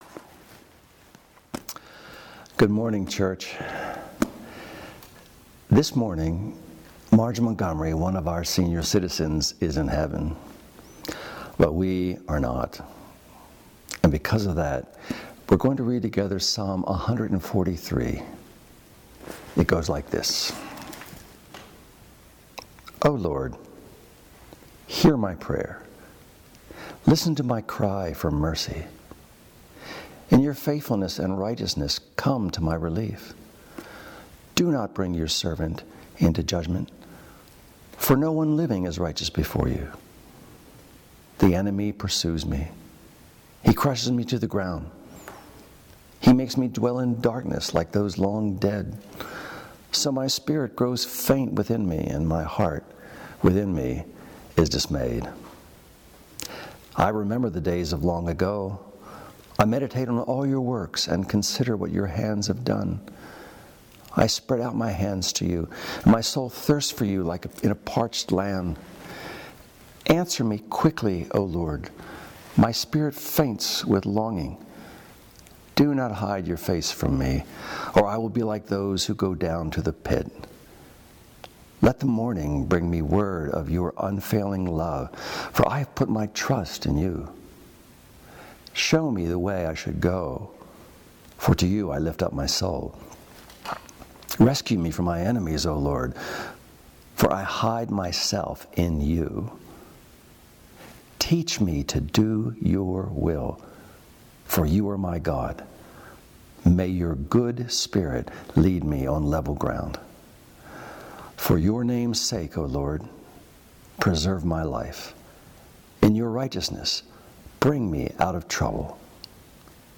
MP3 audio sermons from Brick Lane Community Church in Elverson, Pennsylvania.